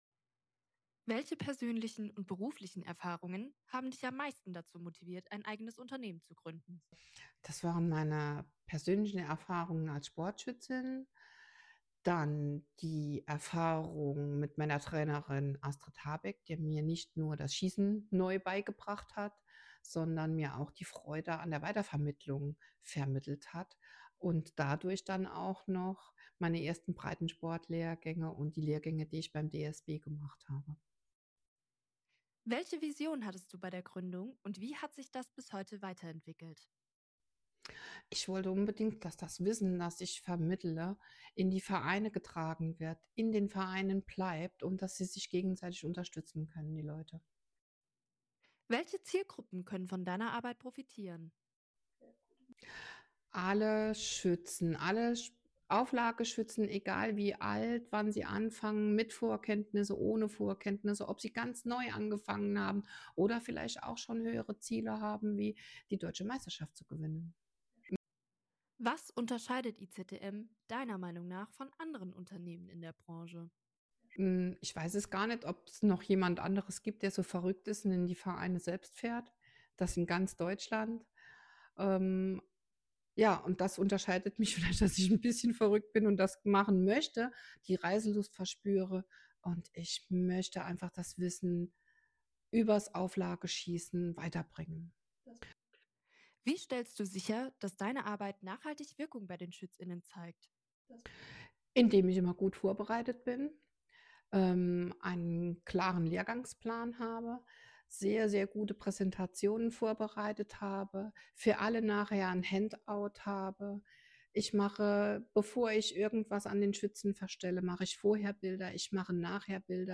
„Meine Leidenschaft IZTM“ – 8 Fragen – 8 Antworten – 3min/18sek. – Live produziert!